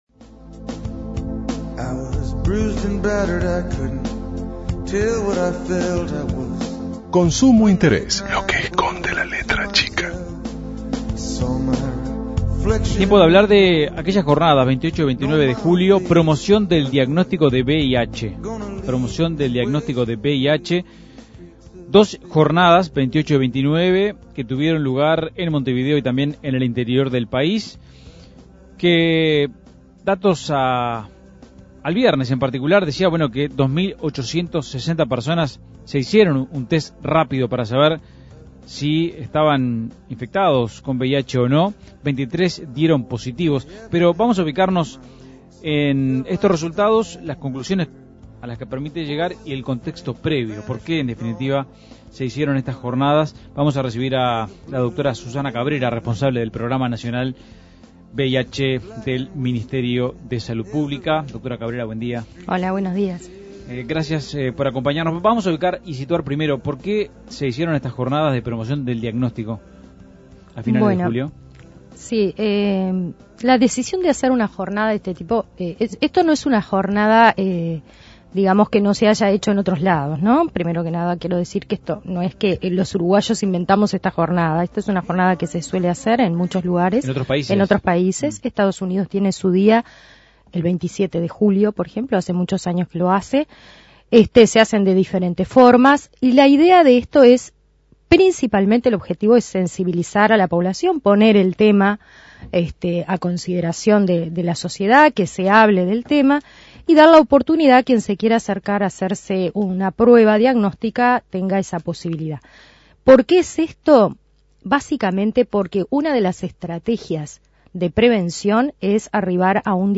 Entrevista a Susana Cabrera, responsable del programa VIH Sida del MSP